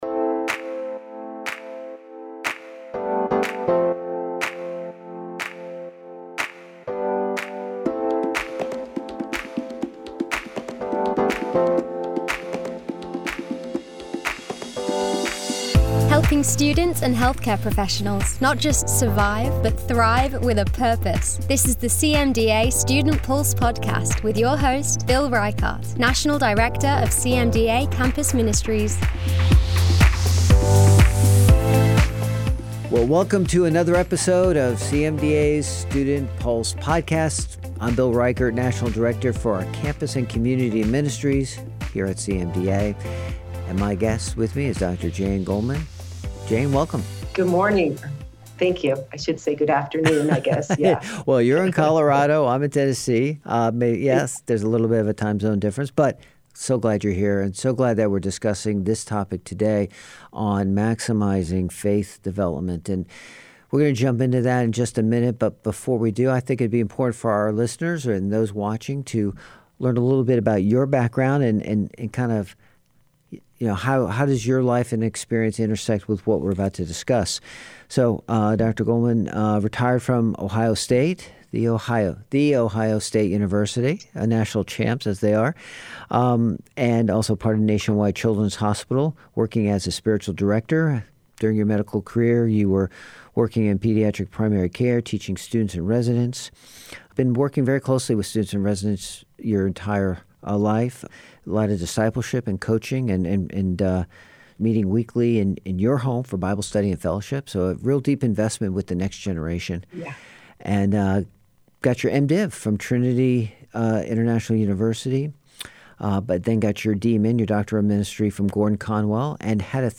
Discover how to integrate spiritual practices into your busy schedule, find strength and solace in your beliefs, and connect with a supportive community. This conversation offers a lifeline of hope and guidance for anyone striving to maintain their spiritual foundation while navigating the fast lane of healthcare training.